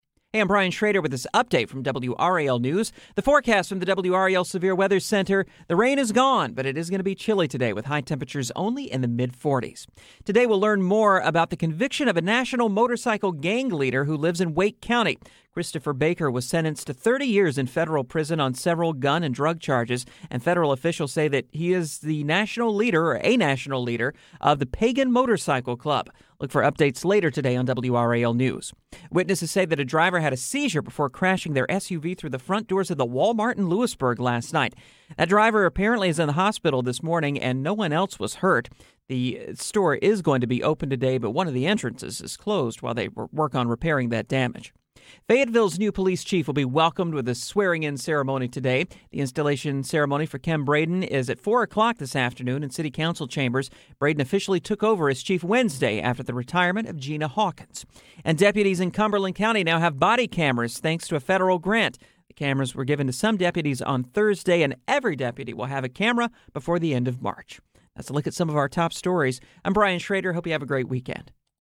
WRAL Newscasts